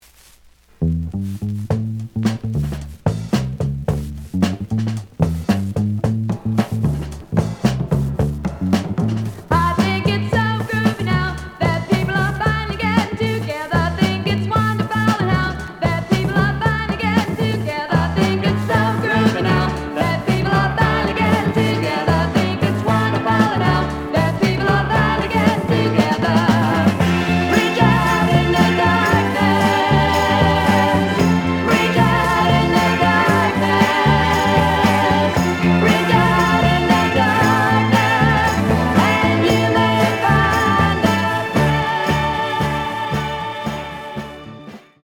The audio sample is recorded from the actual item.
●Genre: Rock / Pop
Looks good, but some noise on A side.)